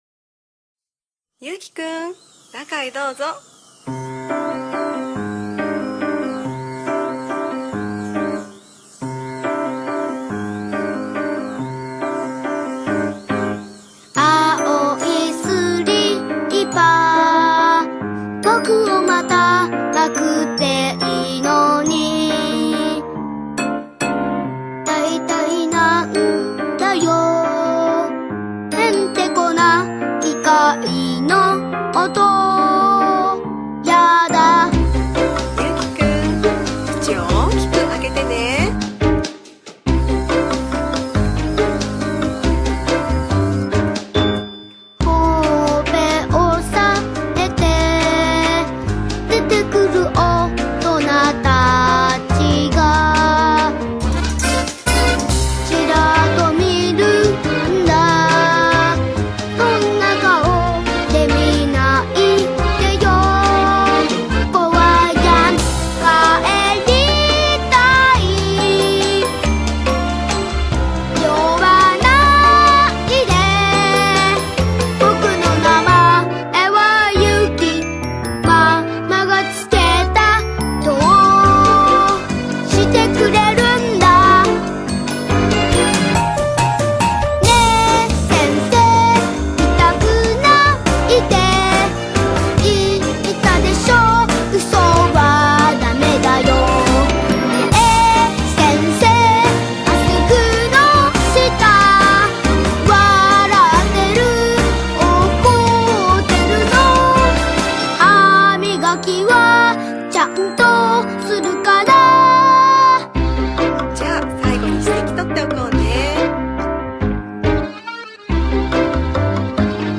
BPM140
Audio QualityPerfect (Low Quality)
10/1/2025: Added SSC file and 3/4 time signature.